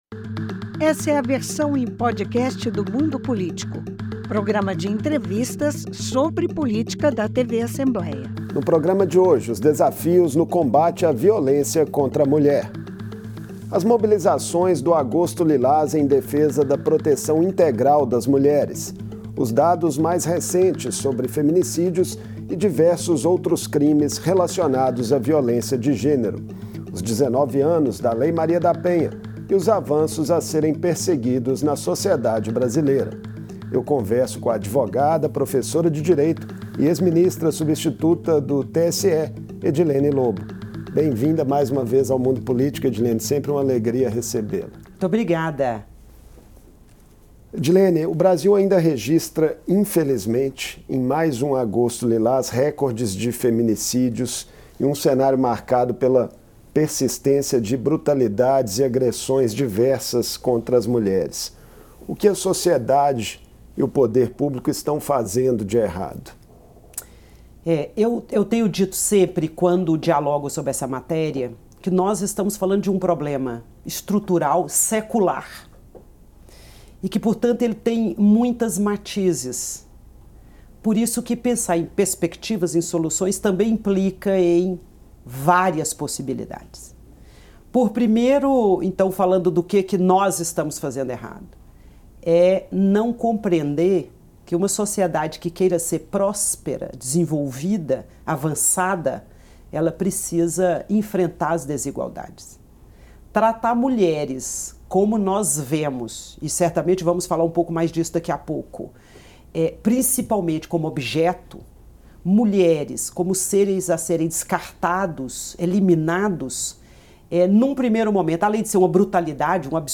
A violência contra a mulher é um problema secular que o Brasil precisa enfrentar. Com esse argumento, a advogada e professora, ex-ministra substituta do TSE, Edilene Lôbo abre a entrevista ao Mundo Político, em agosto, mês de conscientização no combate à violência contra a mulher. A advogada diz que uma sociedade tem que superar as desigualdades entre homens e mulheres para ser próspera.